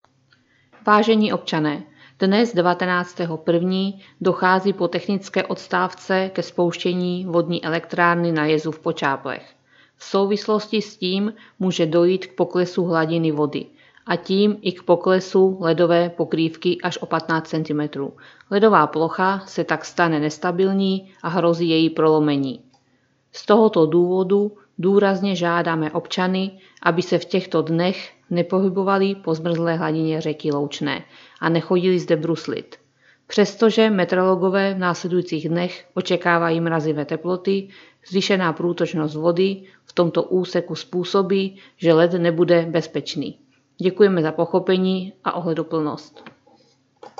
Hlášení městského rozhlasu 19.01.2026